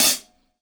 DUBHAT-22.wav